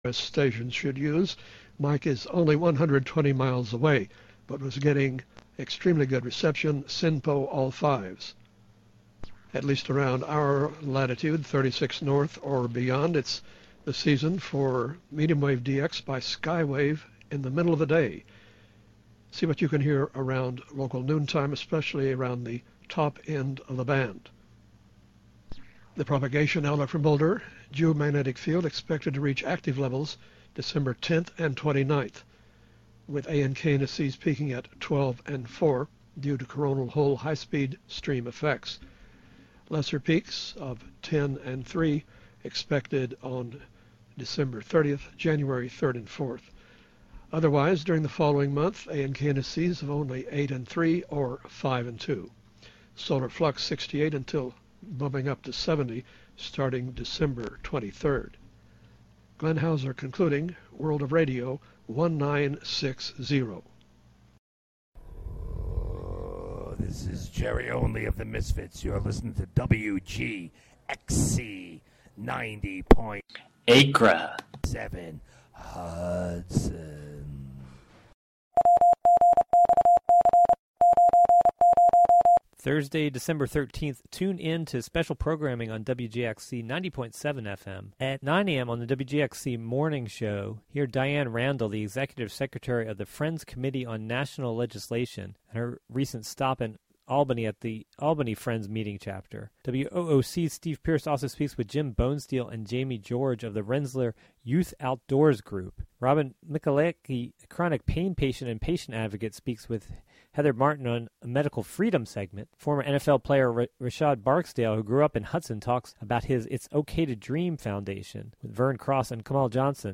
"All Together Now!" is a daily news show covering...
"All Together Now!" features local and regional news, weather updates, feature segments, and newsmaker interviews.